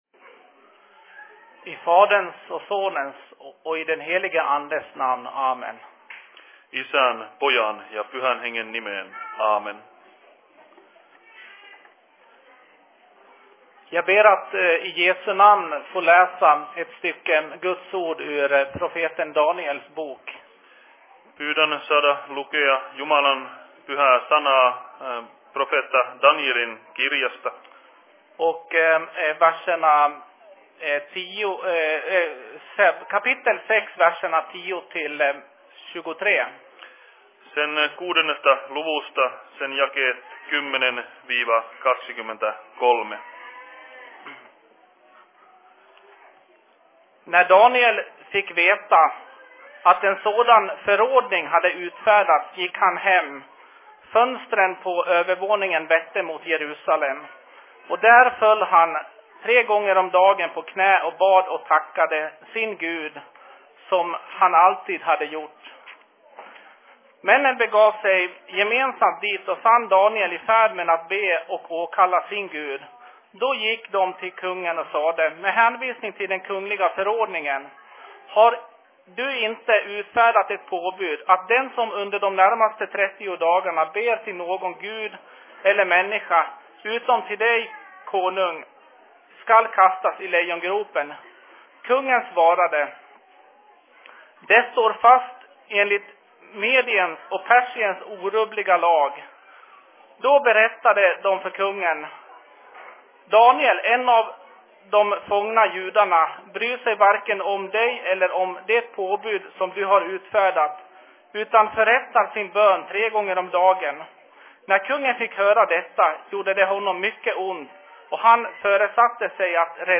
Se Fi Seurapuhe Taalainmaan RY:llä 08.12.2013
Paikka: SFC Dalarna
Simultaanitulkattu Ruotsi, Suomi